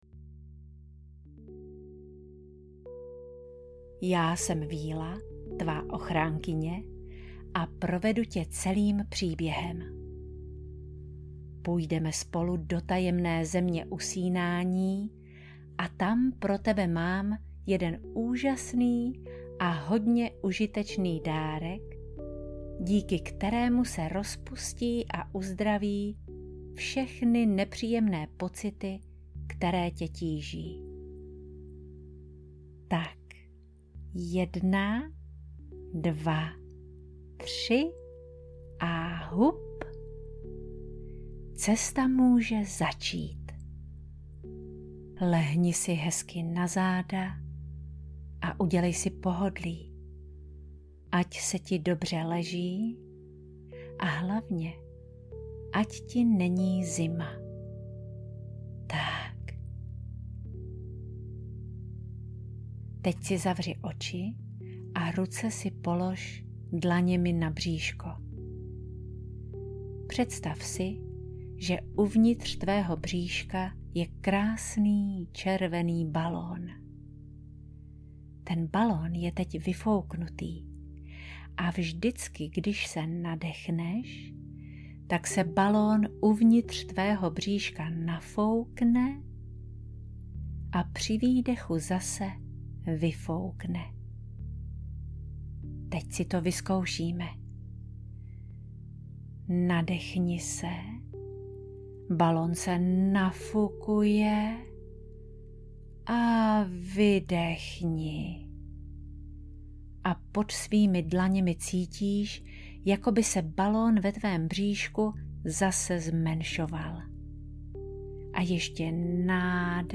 Dárek z čarovné země - uzdravující meditace pro děti audiokniha
Ukázka z knihy